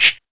PERC.93.NEPT.wav